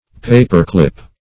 paperclip \pa"per*clip`\ (p[=a]"p[~e]r*kl[i^]p`), n.